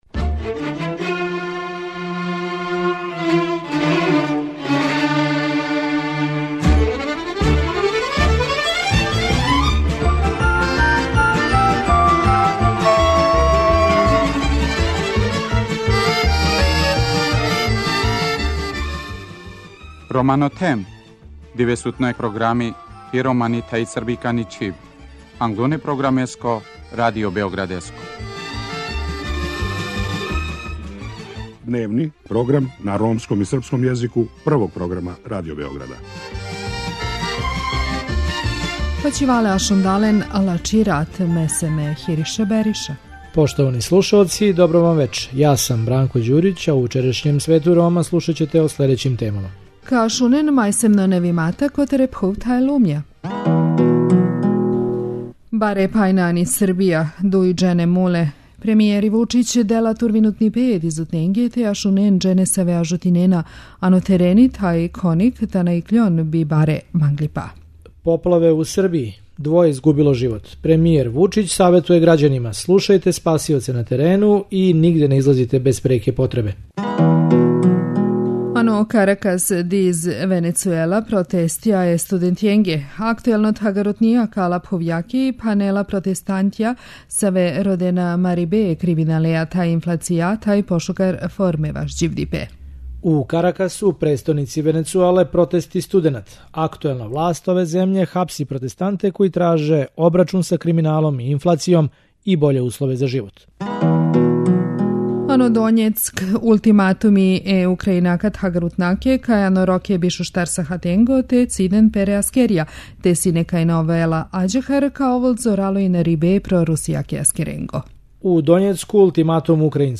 Разговарали смо са становницима овог насеља који се боре да од воде спасу оно што се може спасти.